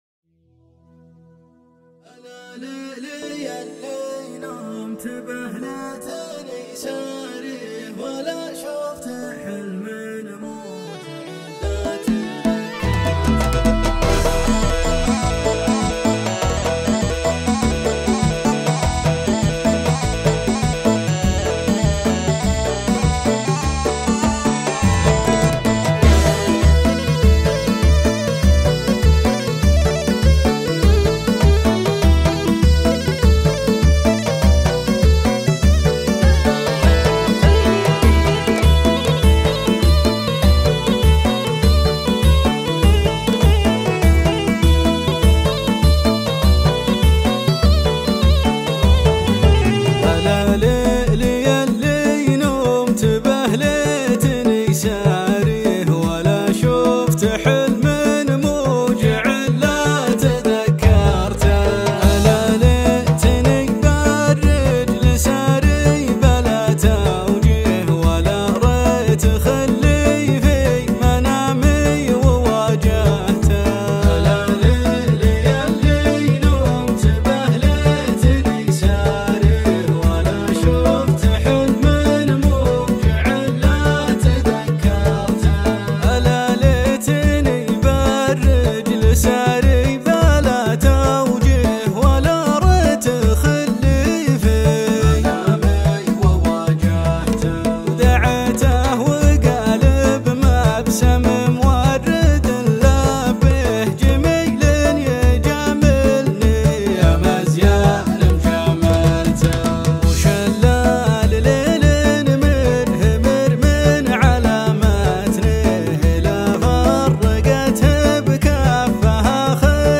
شيلات طرب